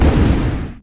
Index of /App/sound/monster/skeleton_magician
attack_2_explo.wav